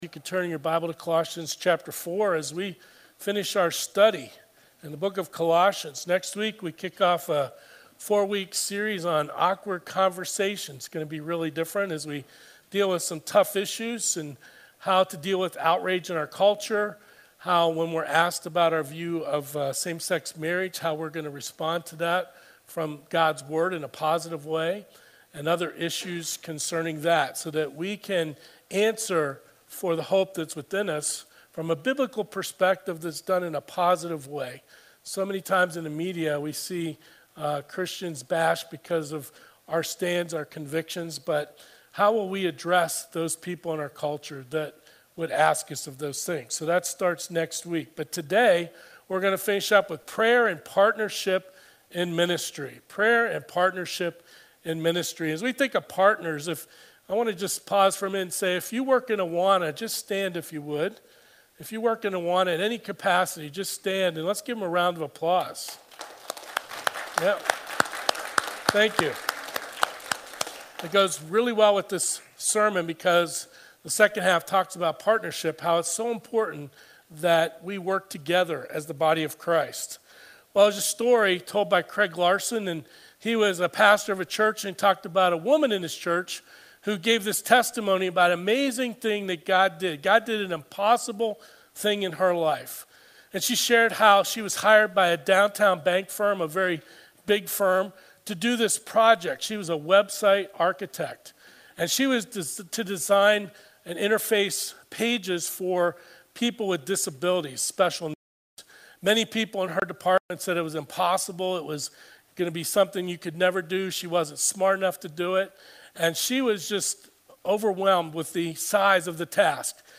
Sermons | Pleasant View Baptist Church